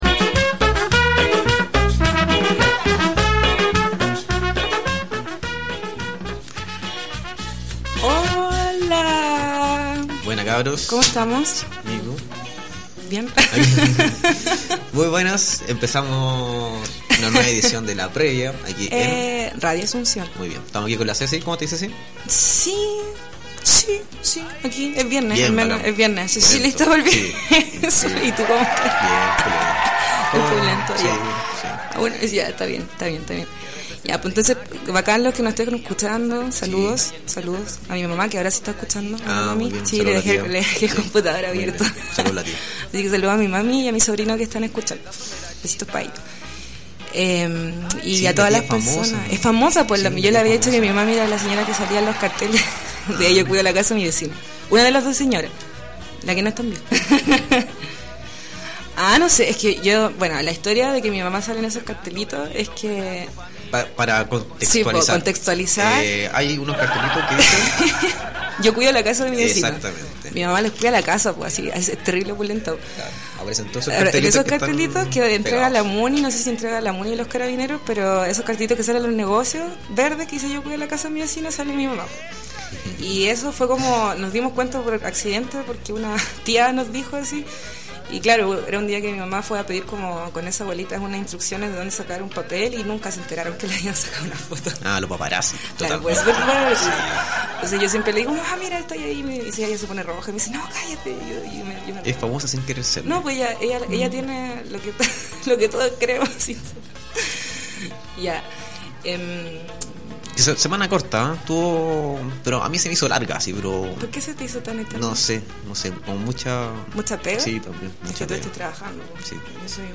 Conversamos sobre diversos temas relacionados con nuestra ciudad Villa Alemana y Peña Blanca, desde la perspectiva de la Previa. Sumate y escuhanos cada viernes en vivo desde las 20:30 hrs.